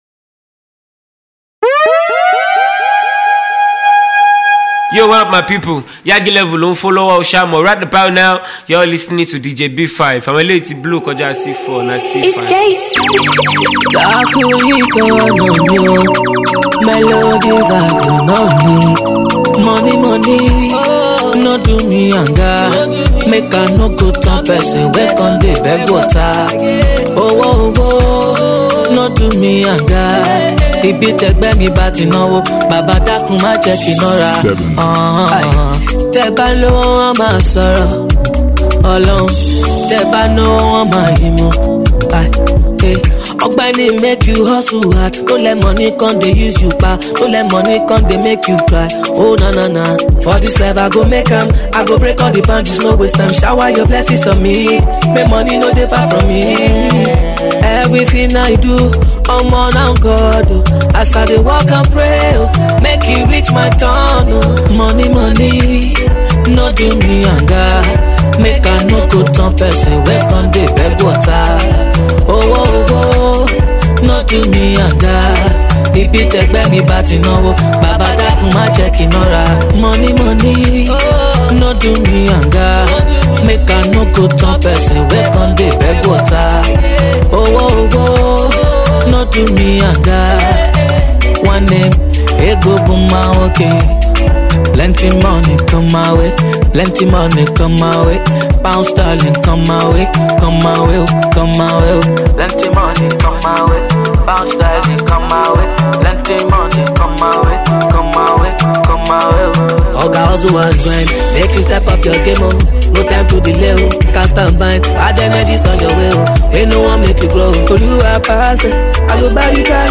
Nigerian disc jockey
hot evergreen and new hits